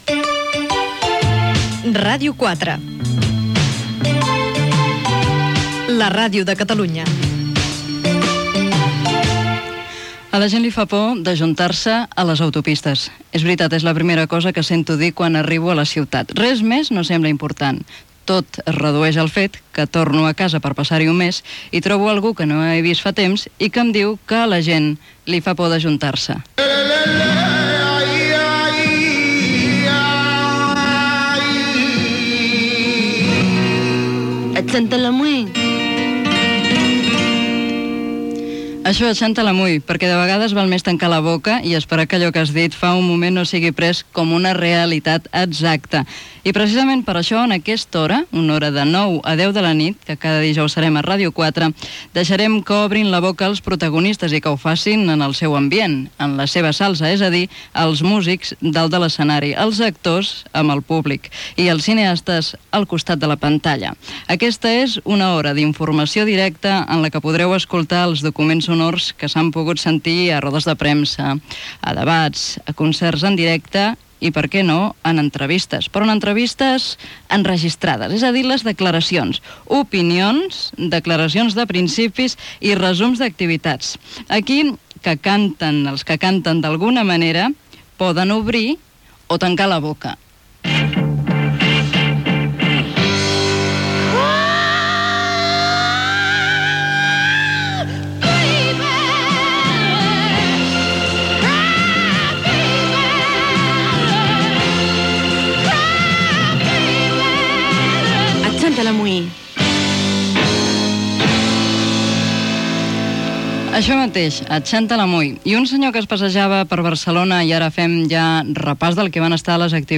Indicatiu de la ràdio, fragment literari, explicació del que oferirà el programa, repàs a l'actualitat cultural de la setmana
Gènere radiofònic Cultura